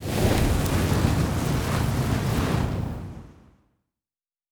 Fire Spelll 24.wav